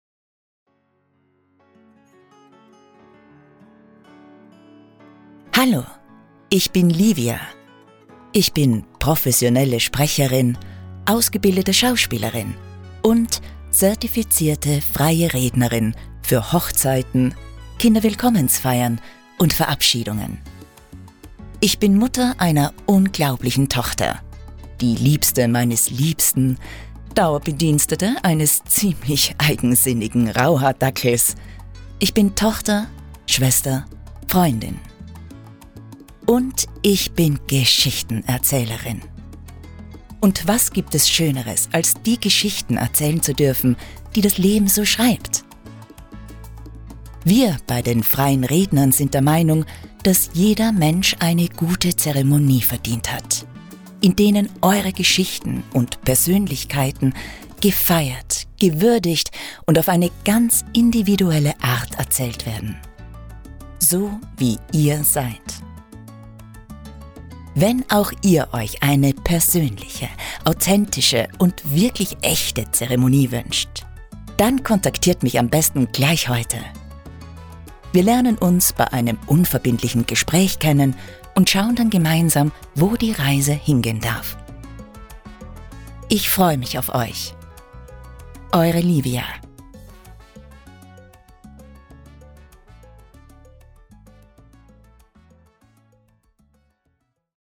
MEINE STIMME
STIMMUNGSVOLL – AUTHENTISCH – ECHT